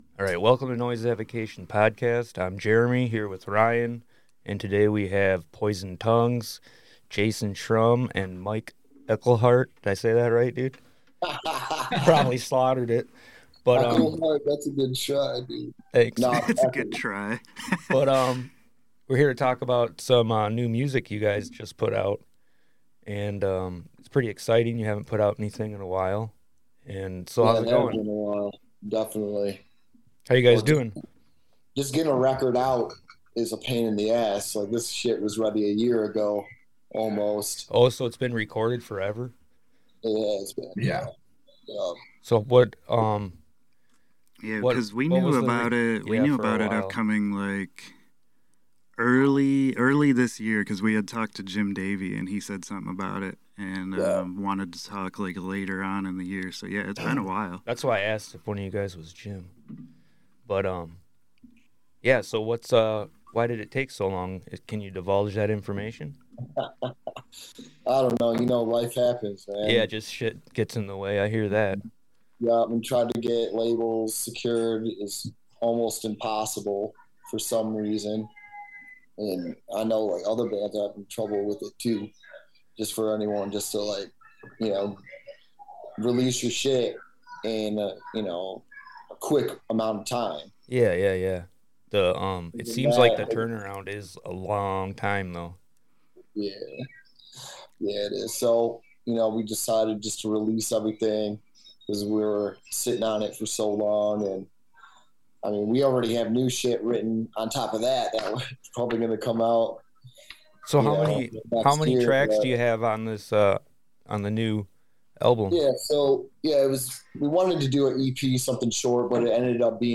Join us in our record store for our weekly episodes covering all different genres and aspects of music.